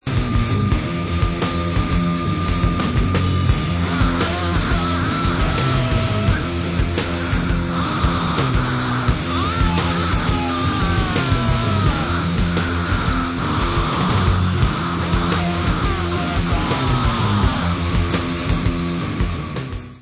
Rock/Alternative Metal